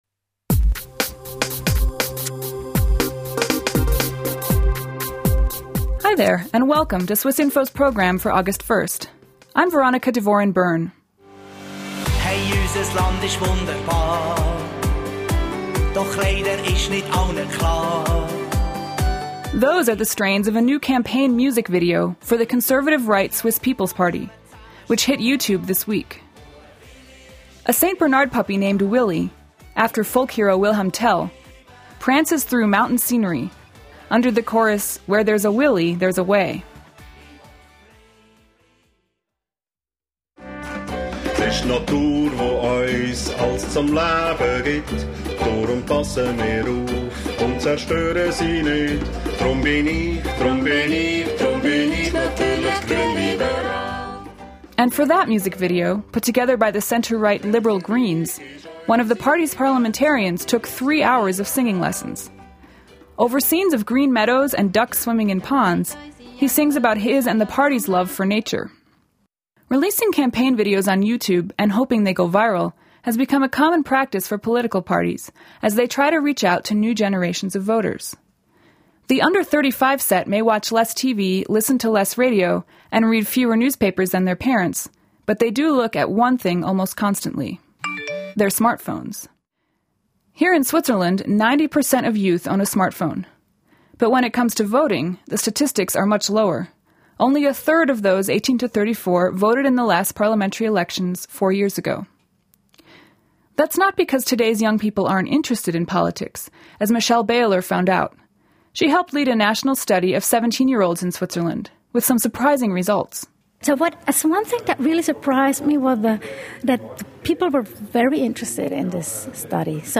We take a road trip across Switzerland to hear from young people - and young Swiss abroad - ahead of upcoming national elections.